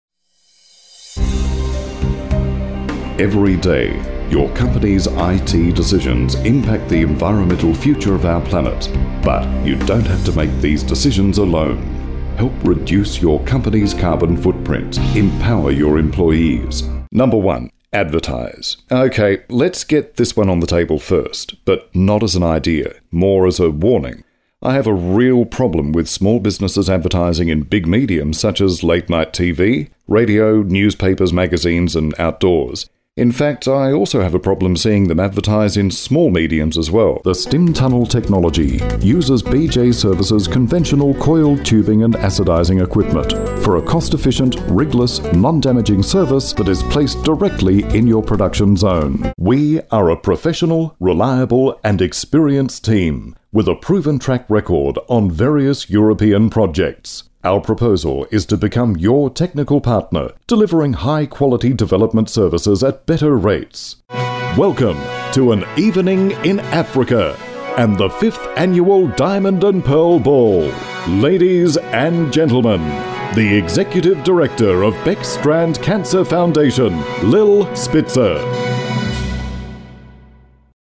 Strong, crisp,versatile, convincing, authoritative, cheeky voice artist
englisch (australisch)
Sprechprobe: Industrie (Muttersprache):